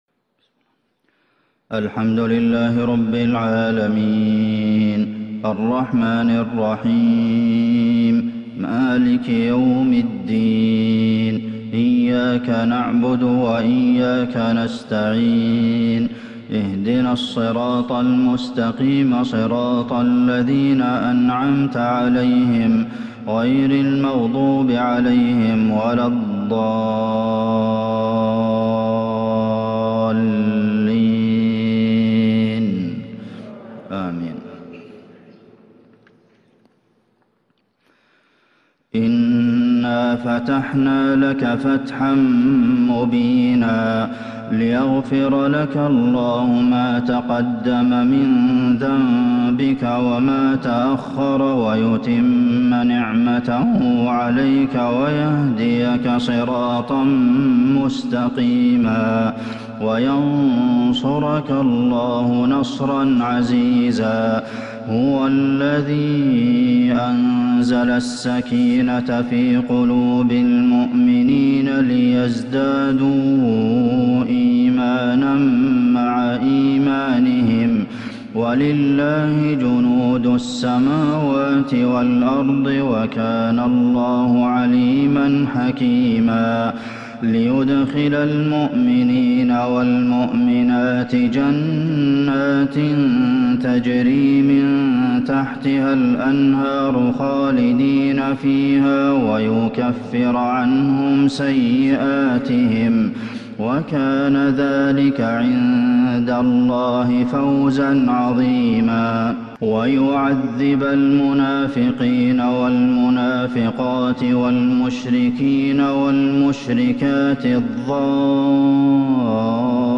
فجر الخميس 1-6-1442هـ من سورة الفتح | Fajr prayer from Surah al-Fath 14/1/2021 > 1442 🕌 > الفروض - تلاوات الحرمين